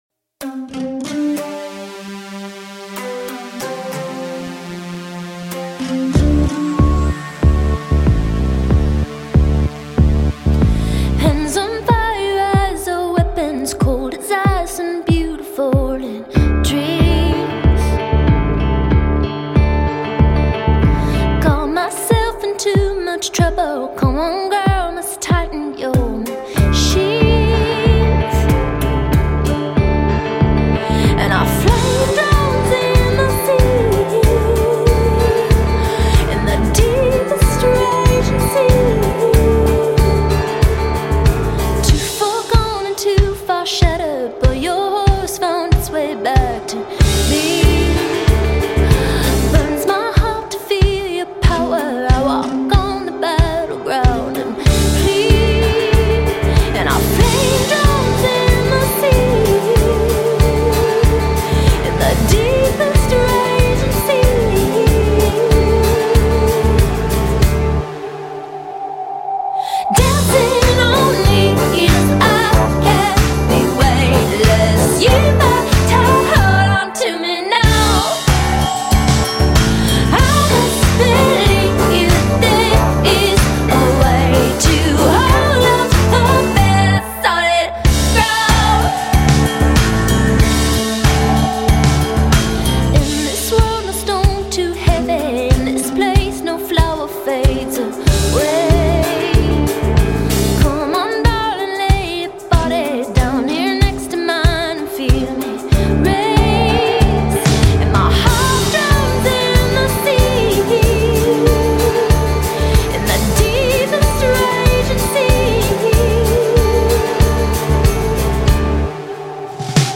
it´s the perfect mix of straight up piano and production.
wonderfully, beautifully melancholy.